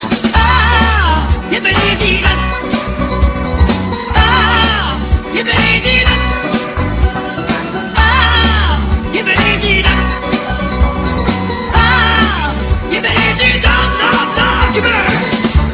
Voix rebelle, enrouée de blues